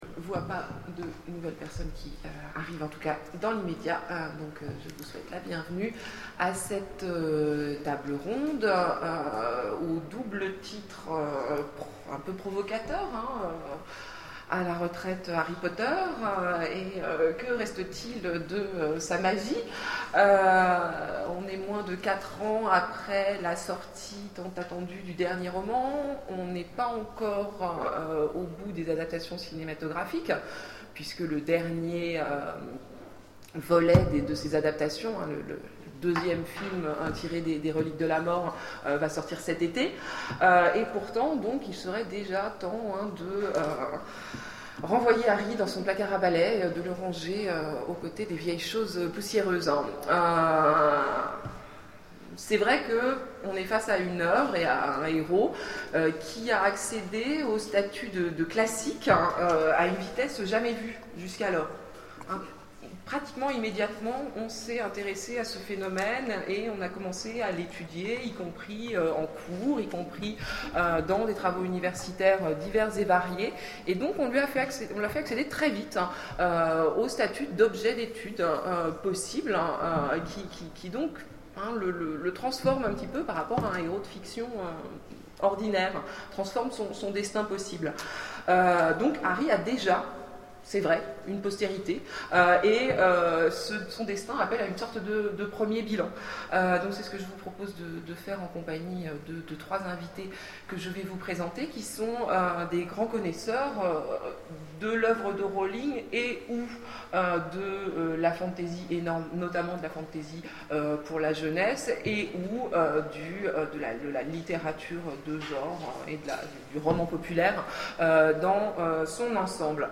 Voici l'enregistrement de la conférence " Le petit sorcier à la retraite... Que reste-t-il de la magie d'Harry Potter ? ".